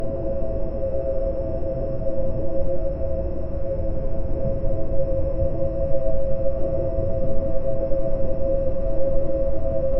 Ambient soundscape: abandoned polar station at night. Deep drones, cold silence, distant wind, metal creaks, faint radio static, subtle digital glitches. Slow, still, melancholic, mysterious.
ambient-soundscape-abando-f7ueglay.wav